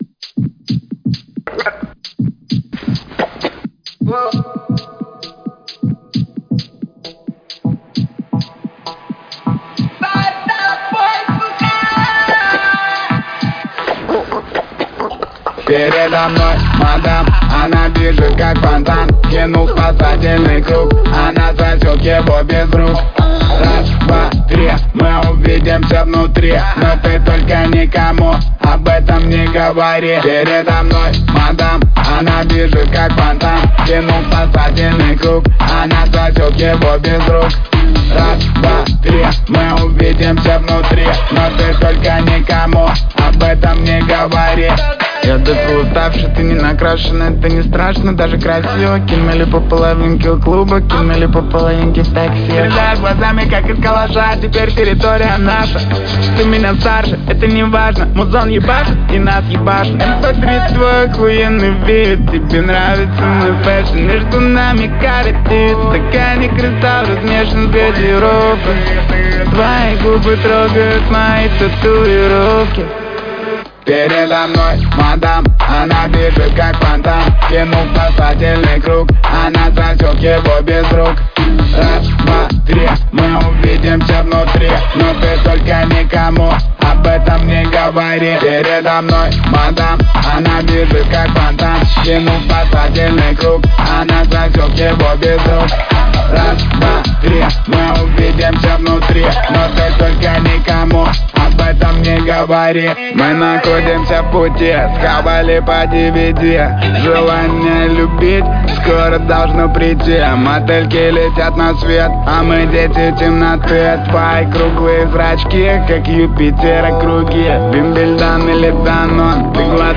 предпочитающей стиль рэп.